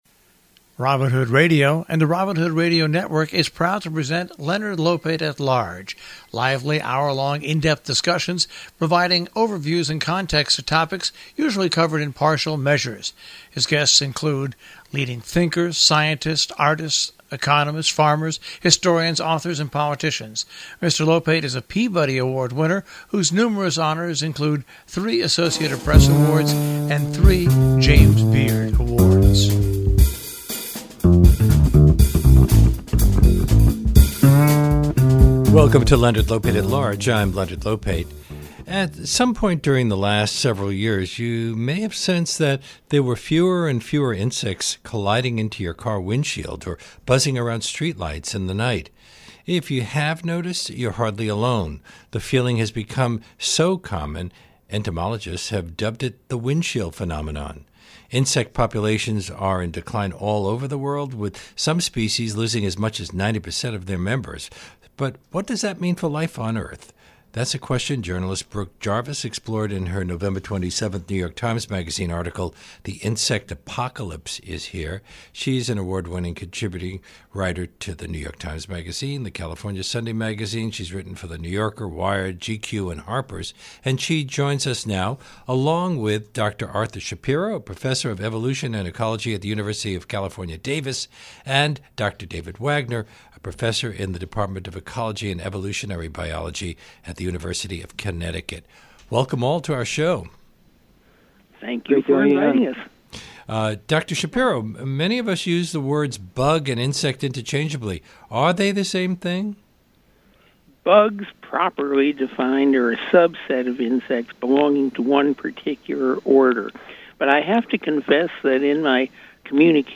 for a discussion of what is behind this massive insect disappearance.